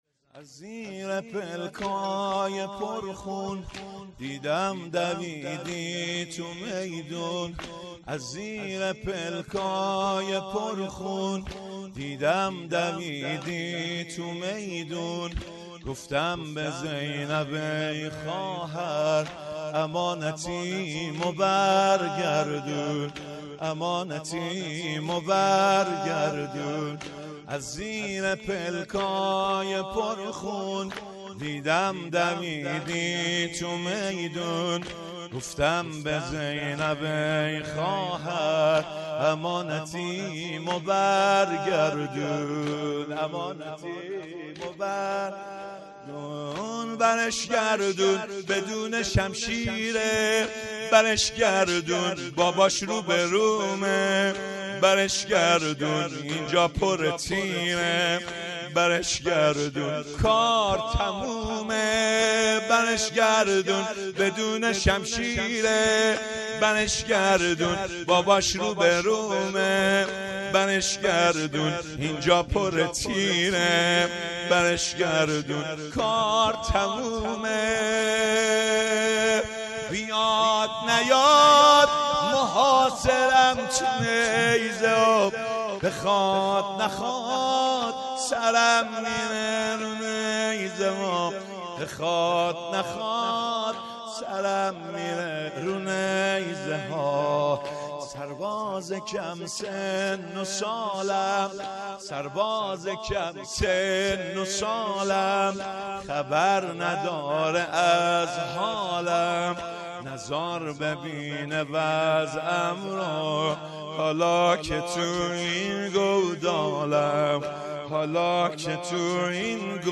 هیئت عقیله بنی هاشم سبزوار - شورپایانی
شب پنجم_ دهه اول محرم۱۴۰۳